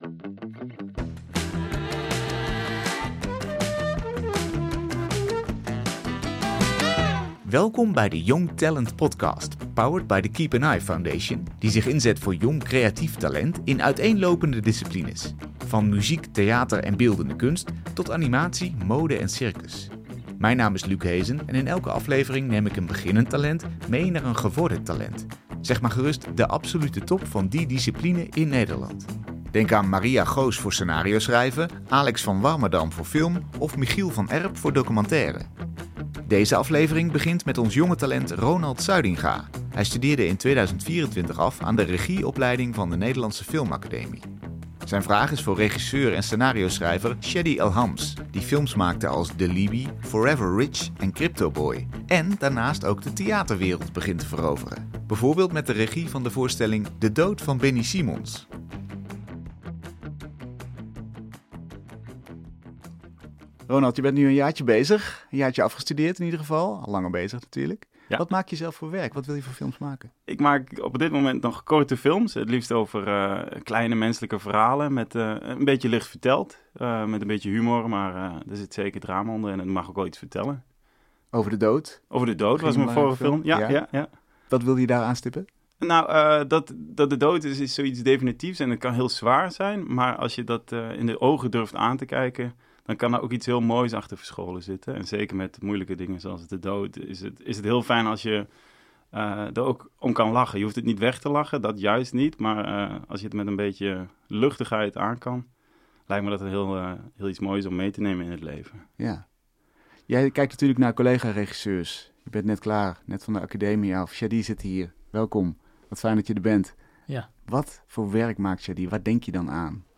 In deze serie gaan jonge talenten in gesprek met mensen uit de kunst en cultuur die al een naam opgebouwd hebben.